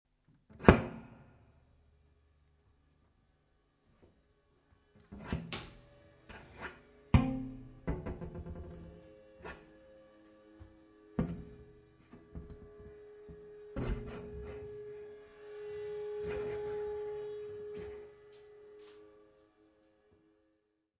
saxofone, soprano, tenor
doublebass
drums, percussion
Music Category/Genre:  New Musical Tendencies